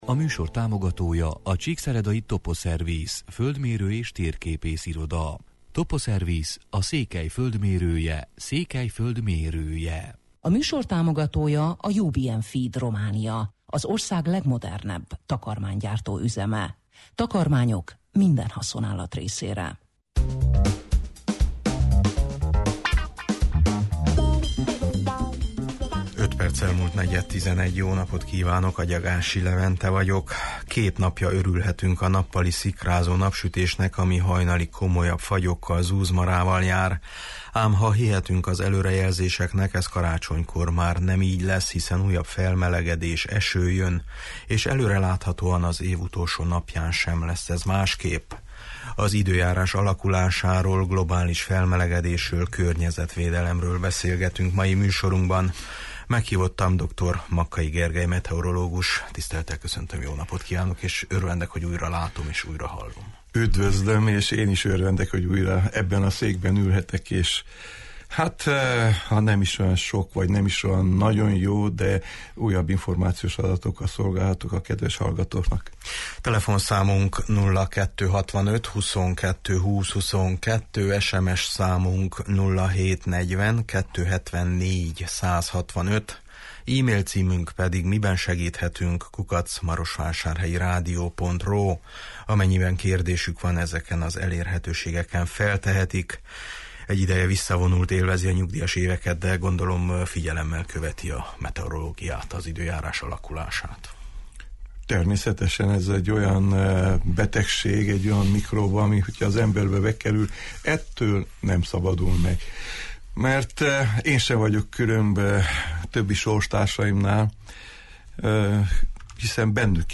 Az időjárás alakulásáról, globális felmelegedésről, környezetvédelemről beszélgetünk mai műsorunkban.